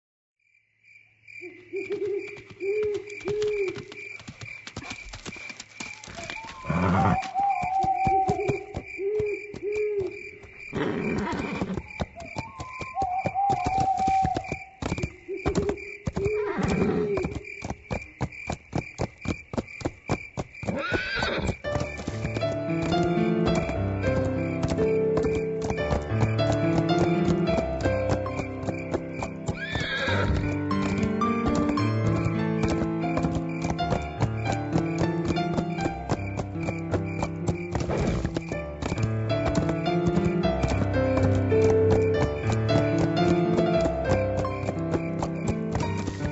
Дикая лошадь мчится ночью под музыку